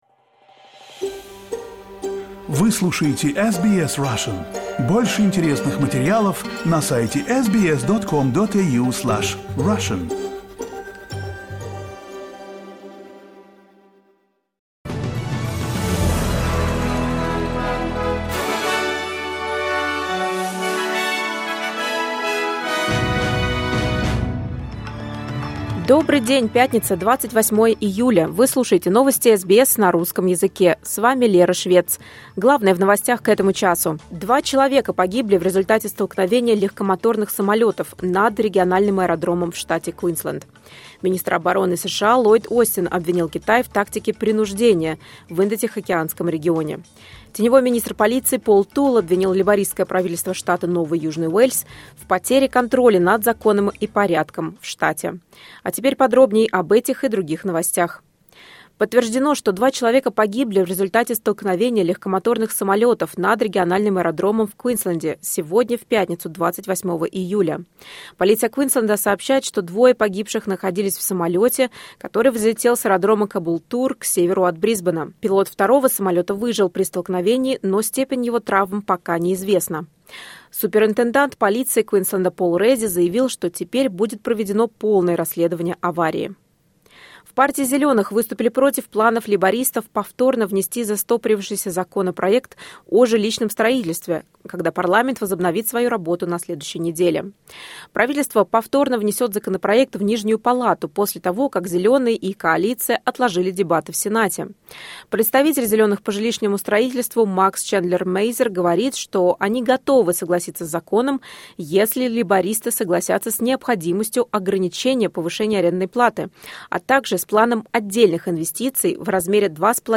SBS news in Russian — 28.07.2023